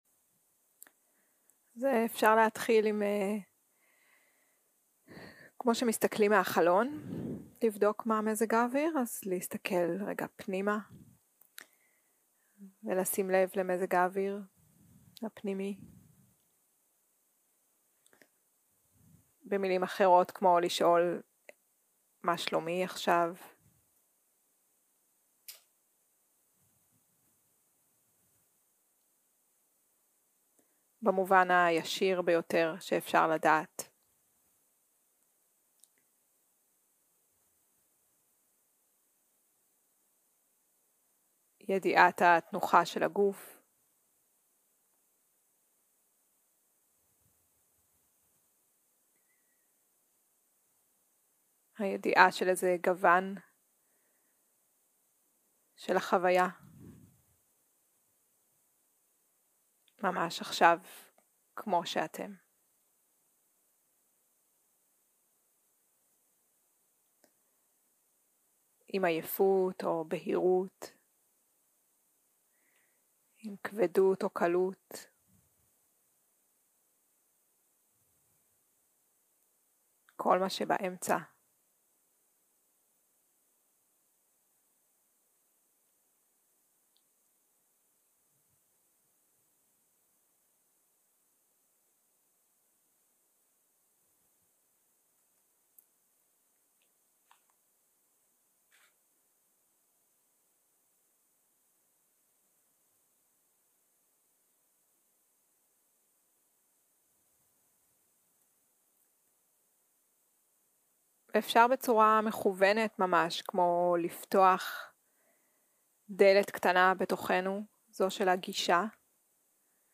יום 3 - הקלטה 6 - צהרים - מדיטציה מונחית
סוג ההקלטה: מדיטציה מונחית